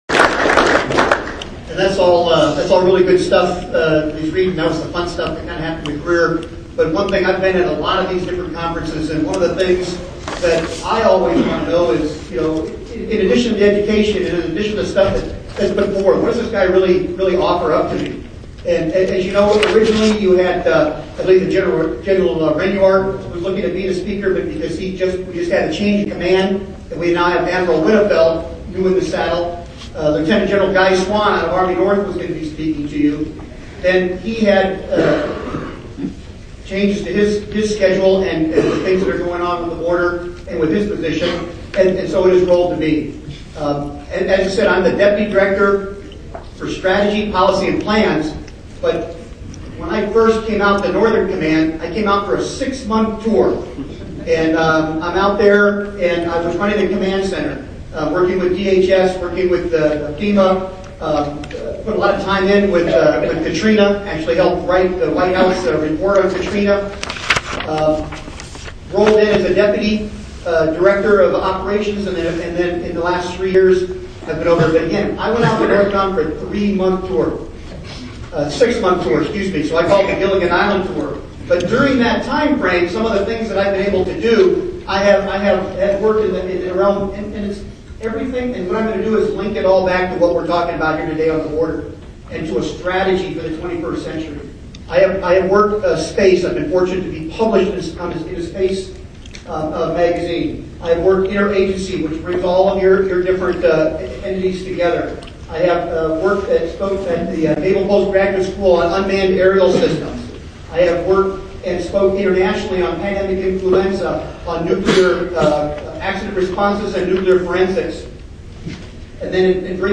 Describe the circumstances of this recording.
Speech at Border Management Conference, May 25, 2010